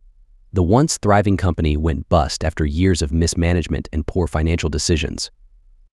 Play.ht-The-once-thriving-company-went-bust-after.wav